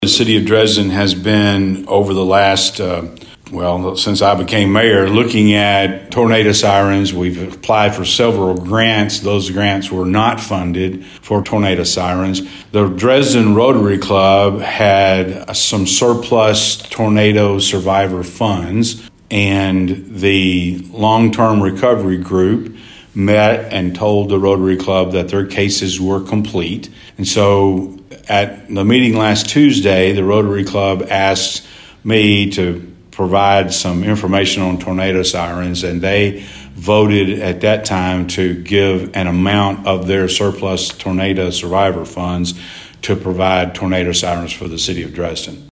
Dresden Mayor Mark Maddox gave Thunderbolt News more information about the donation.